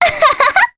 Mediana-Vox_Happy2_jp.wav